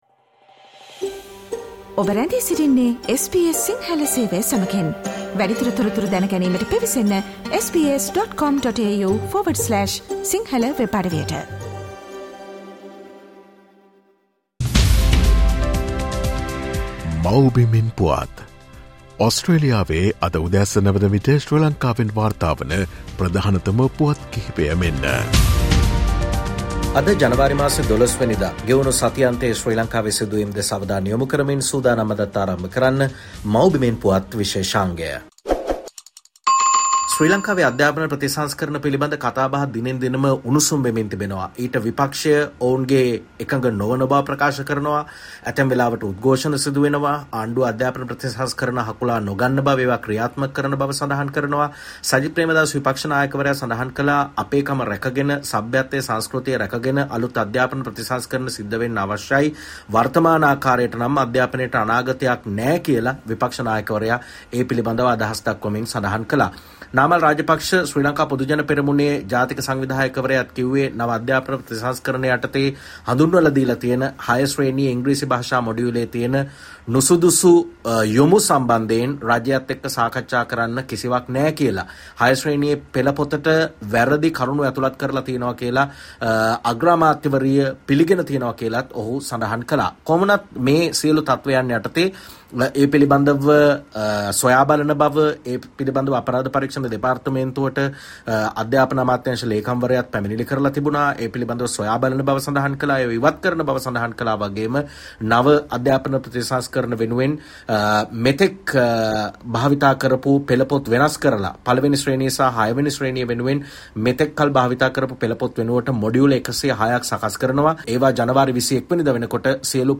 ගෙවුණු සතිඅන්තයේ ශ්‍රී ලංකාවෙන් වාර්තා වූ උණුසුම් හා වැදගත් පුවත් සම්පිණ්ඩනය.